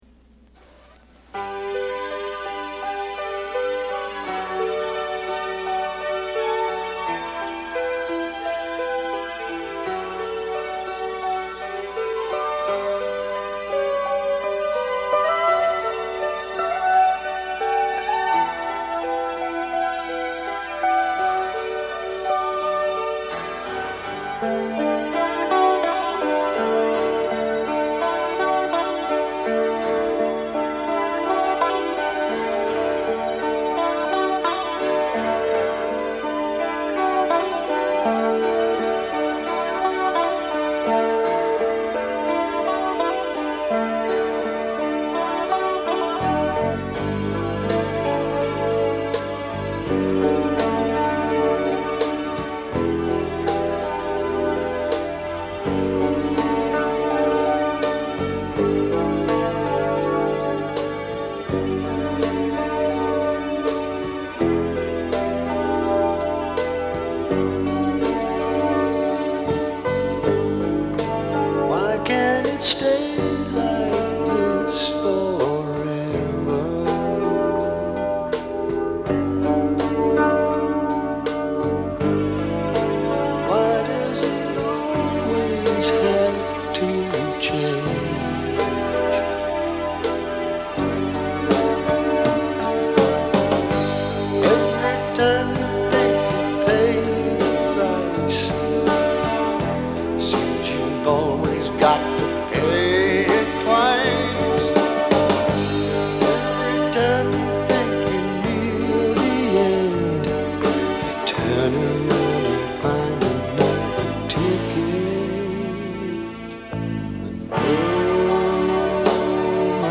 ROCK / BRITISH ROCK / BLUES